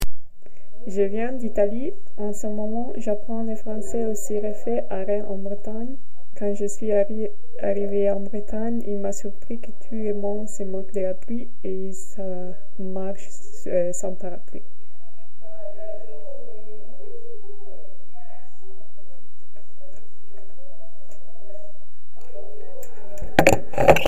Cabine de témoignages
Témoignage du 24 novembre 2025 à 19h19